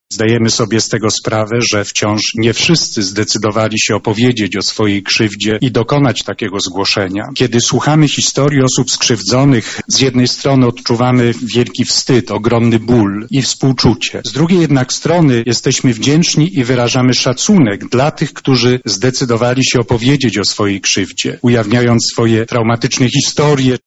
Dla wielu osób zgłoszenie tej sprawy to powrót do bolącej rany zadanej często wiele dekad temu – mówi Prymas Polski, delegat Konferencji Episkopatu Polski do spraw Ochrony Dzieci i Młodzieży arcybiskup Wojciech Polak: